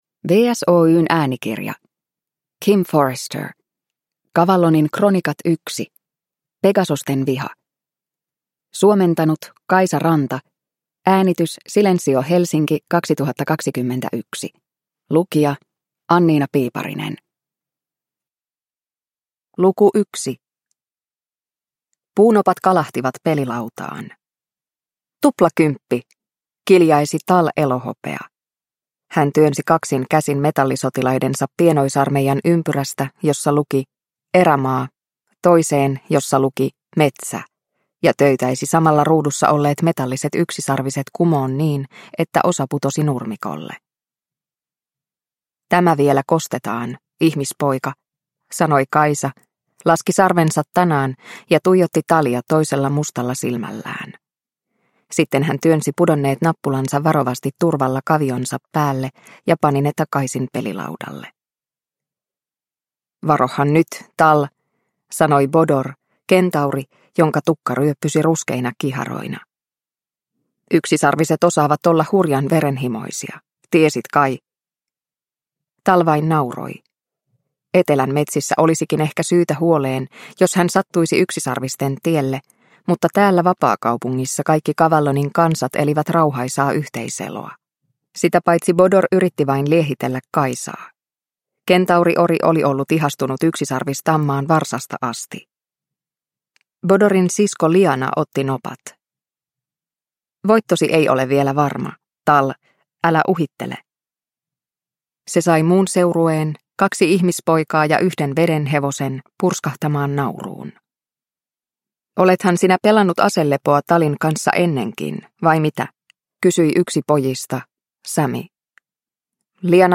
Cavallonin kronikat 1: Pegasosten viha – Ljudbok – Laddas ner